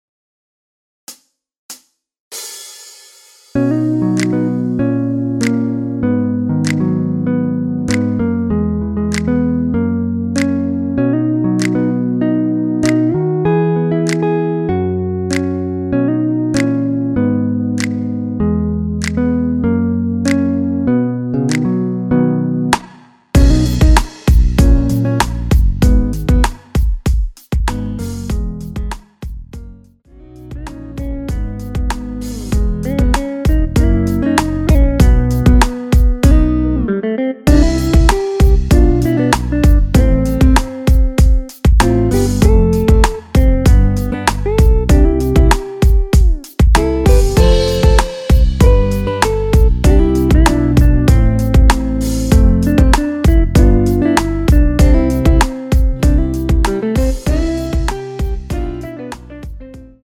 키 B 가수